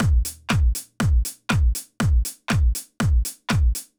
Drumloop 120bpm 10-C.wav